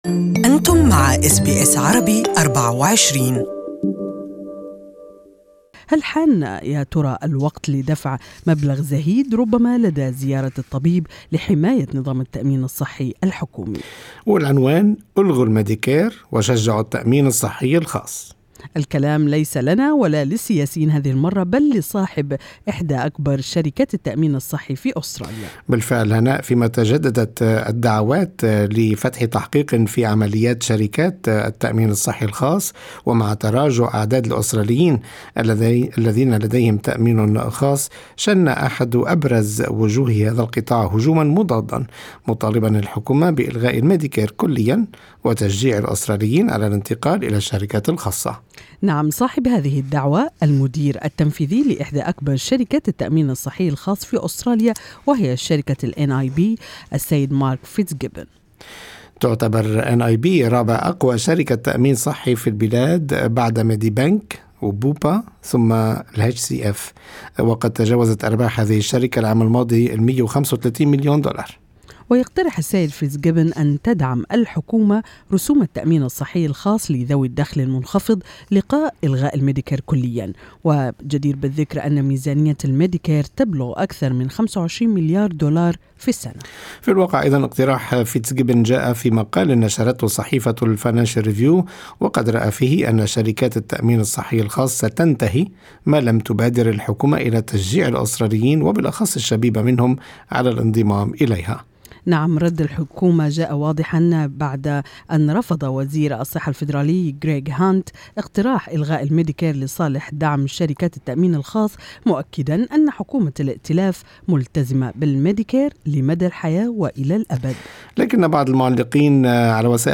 استمعوا إلى الآراء في الرابط الصوتي أعلاه.